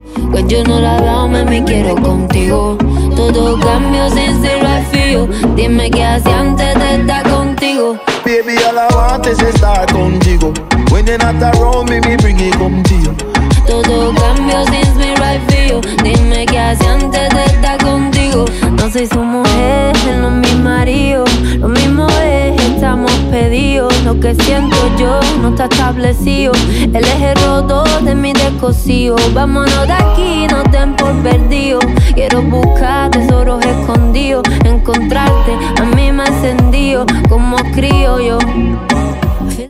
Reguetón